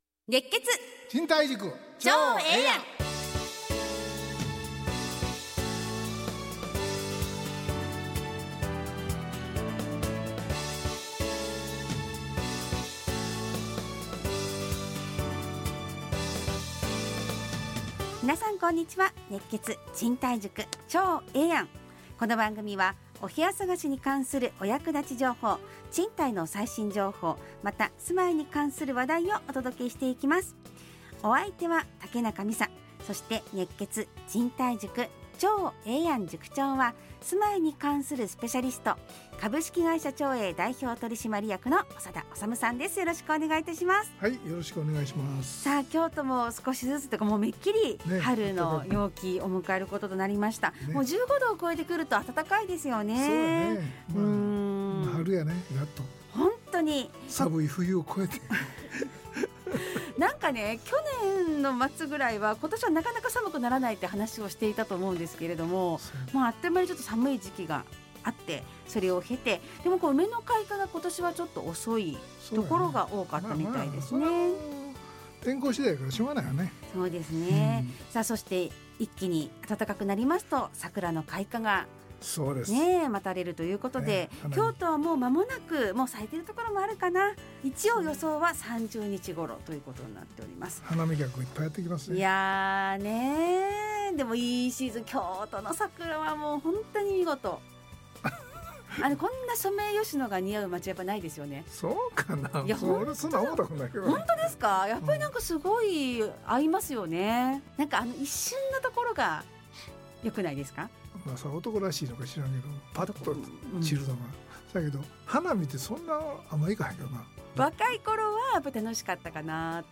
ラジオ放送 2025-03-21 熱血！